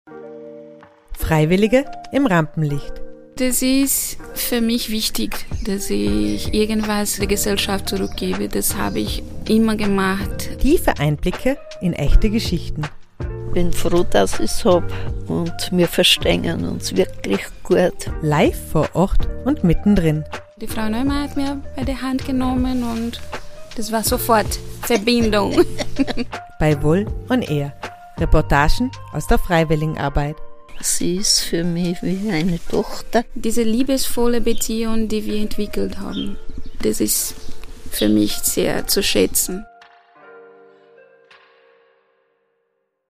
direkt vor Ort, mit viel Interaktion und spannenden Gesprächen.